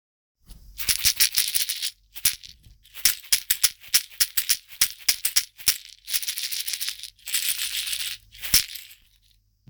ストローがらがら ミニ ダブル
特徴は、身の回りの自然素材を使い全て手作りであること、 人に優しい「倍音」をたっぷり含んだサウンドだということです。
水草を編み上げた小さなカゴの中に、響きのよい小石や種が入っています。丸くカットしたひょうたんが底部分に組み込まれ、ジャカジャカ?♪と優しく心地よい音、自然の音が和みます。南米ででは「カシシ」とも呼ばれているシェーカー。
素材： 水草 ヒョウタン 種 小石